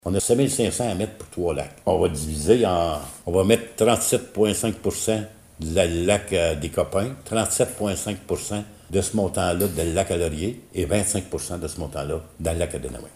Le maire nous parle maintenant de comment a été divisé le montant de 13 000 $ dans les trois lacs choisis :